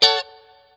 CHORD 2   AH.wav